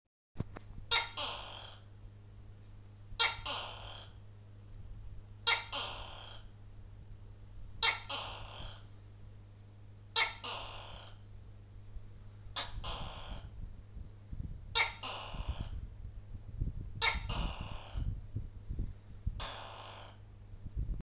The Fuck You gecko in our room. The name comes from it’s sound. He literally tells you to go “fuck you.”